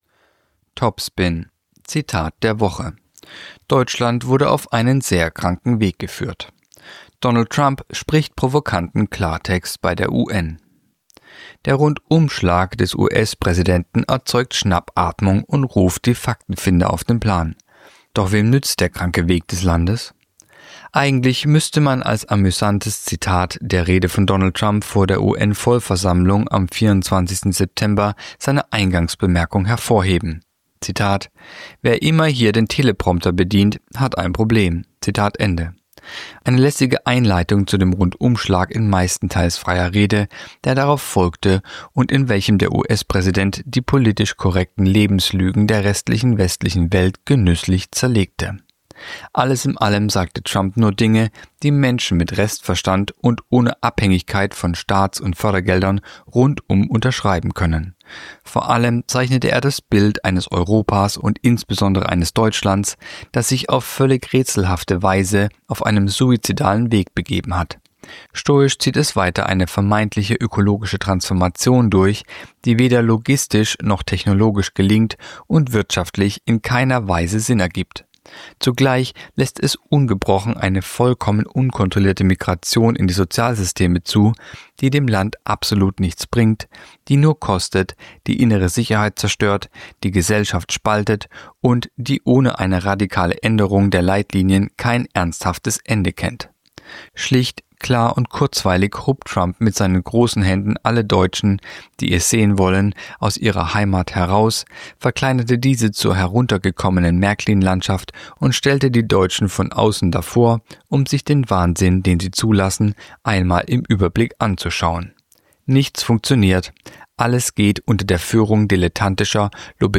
Kolumne der Woche (Radio)„Deutschland wurde auf einen sehr kranken Weg geführt.“